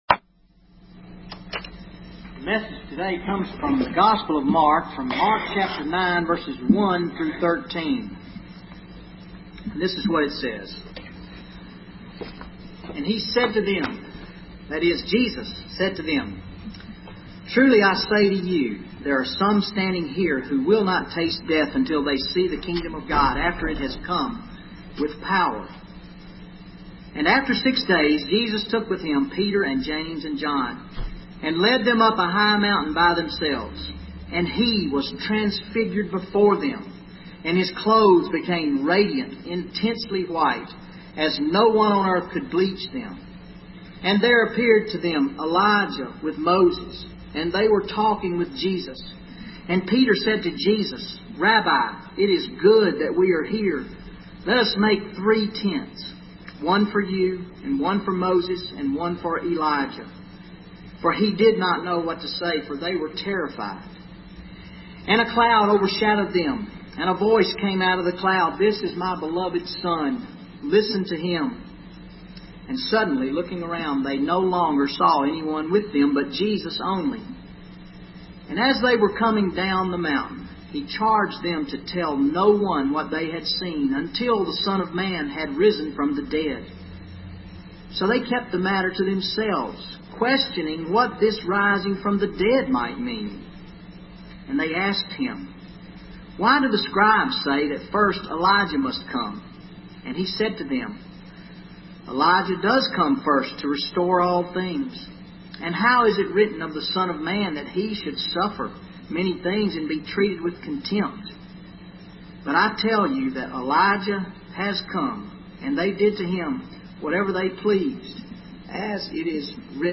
0 Comments VN810127 Sermon Audio Previous post Sermon February 3